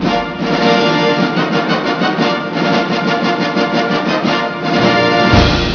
snd_8552_Fox.wav